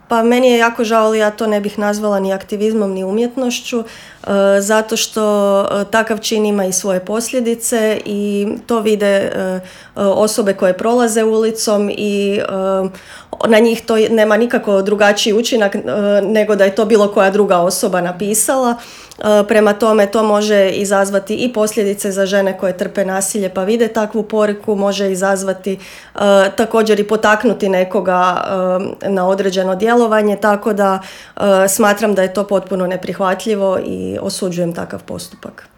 koju smo ugostili u intervjuu Media servisa